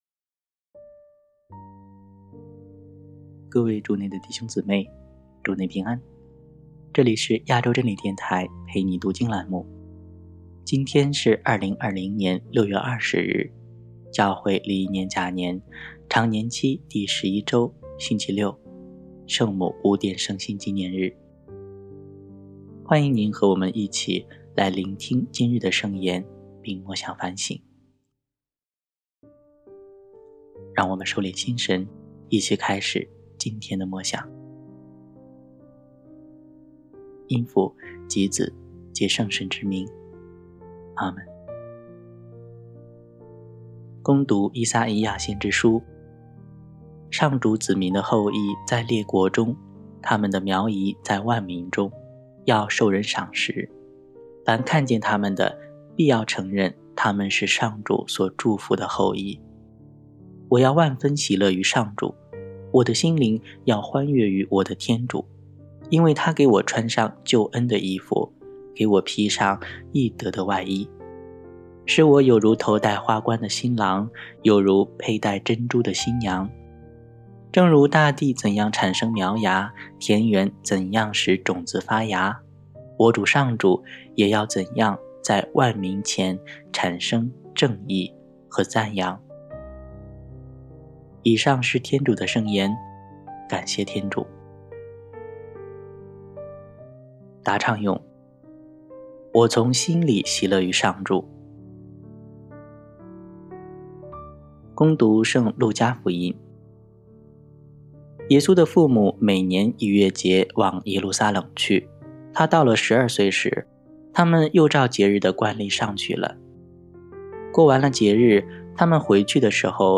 读经&福音